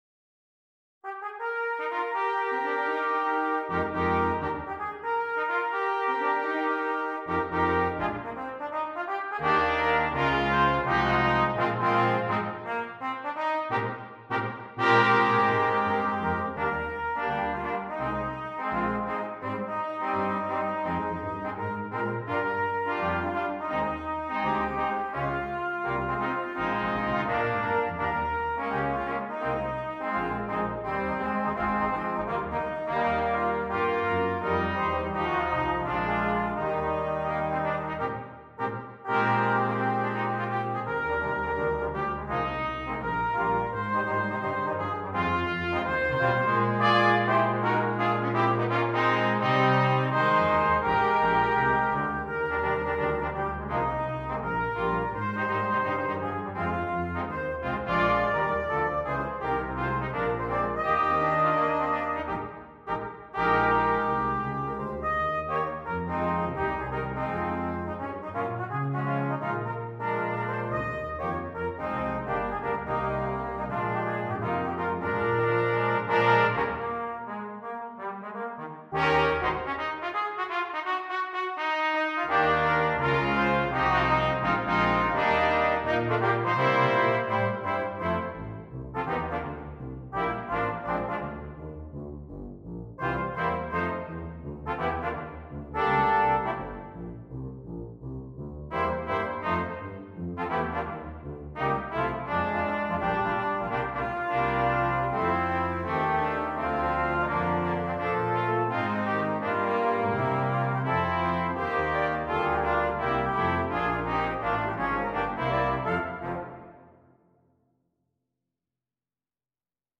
Brass Quintet
This is a great swing arrangement of the chorus